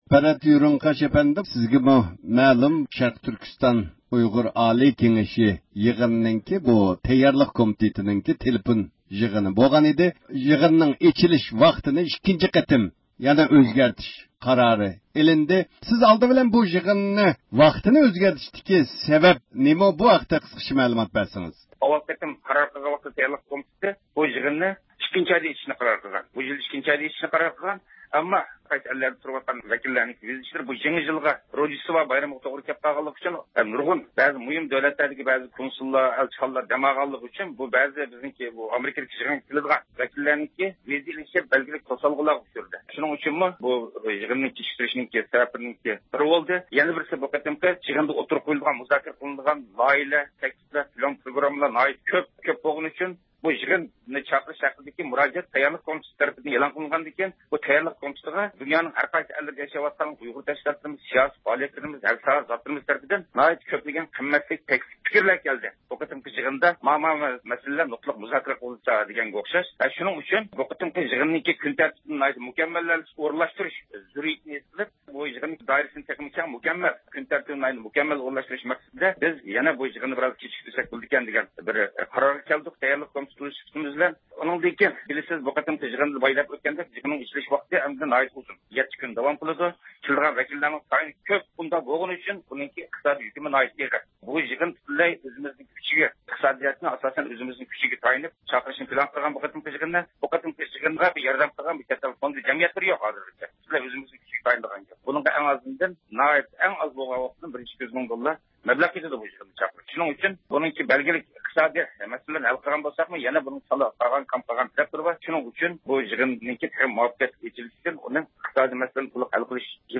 تېلېفون زىيارىتى ئېلىپ باردۇق.